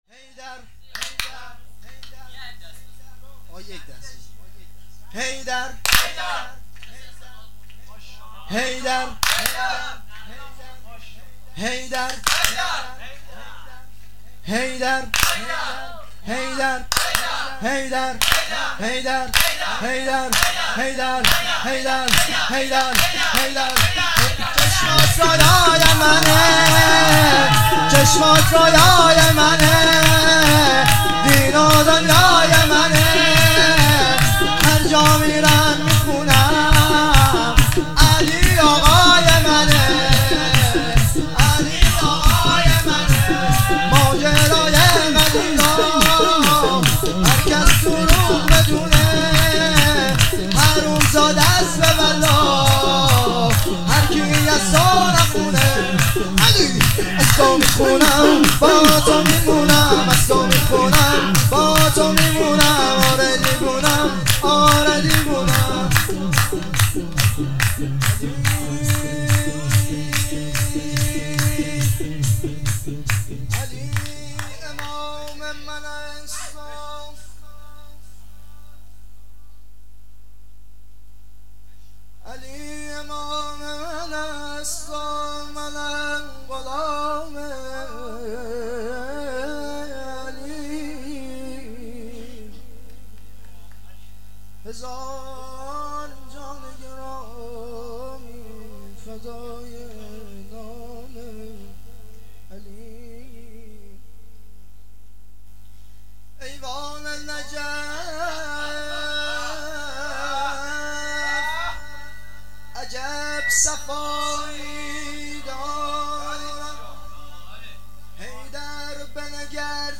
جشن ولادت امام حسن عسکری علیه السلام ۱۴-۹-۹۸